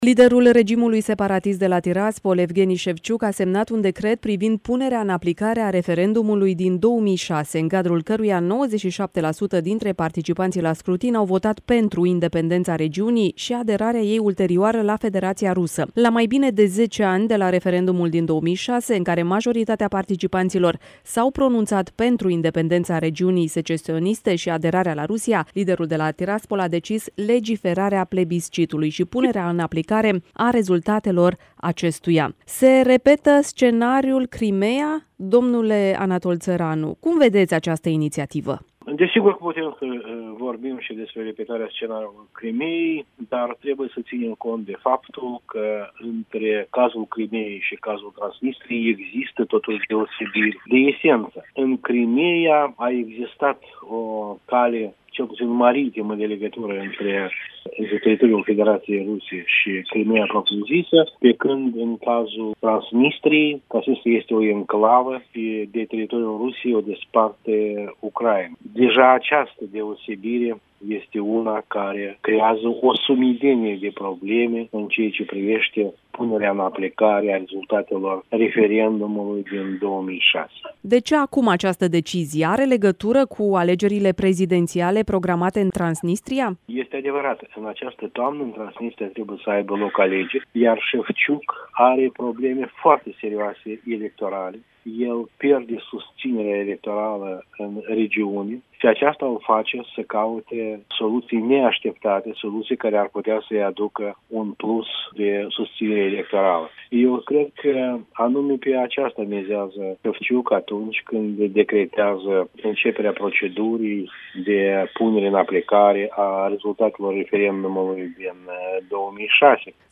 (INTERVIU) Un nou scenariu Crimeea este puțin probabil în cazul Transnistriei, susține Anatol Țăranu, fost negociator șef al Chișinăului cu separatiștii transnistreni